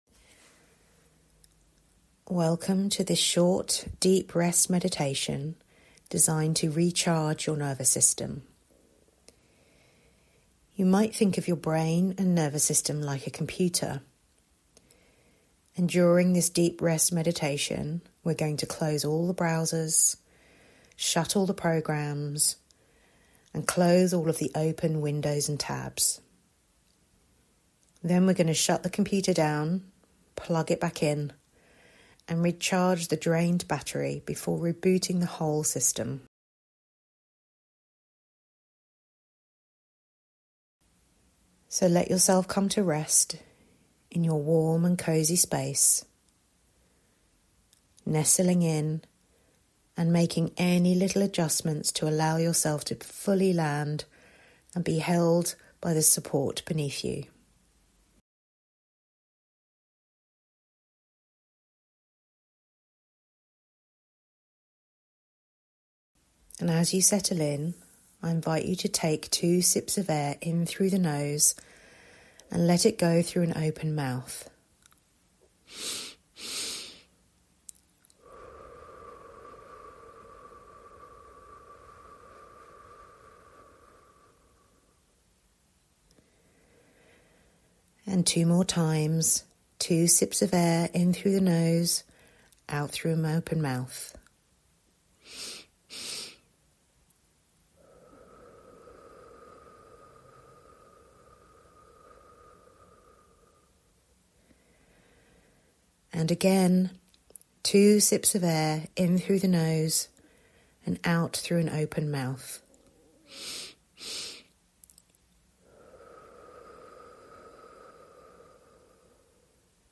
Deep Rest Meditation – nervous system recharge